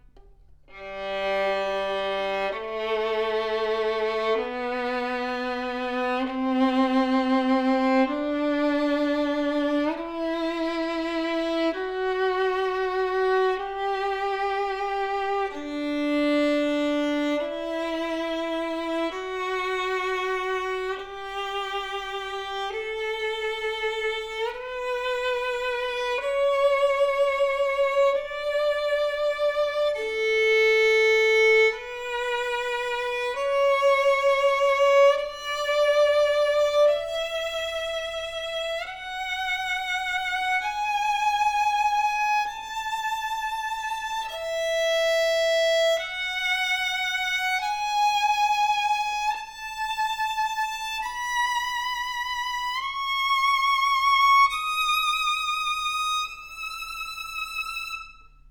Audio clip ( Scale ):
POWERFULL, dark, projective tone with fantastic projection that carries the tone on distance!
Deep ringing G string, great depth and sings with bold dimension. Sweet and focused mid register that speaks with clarity, clean E string with a singing tone quality. A superb Guarneri with a deep strong open voice, guaranteed the best sounding violin with most projection in 1-2k price range!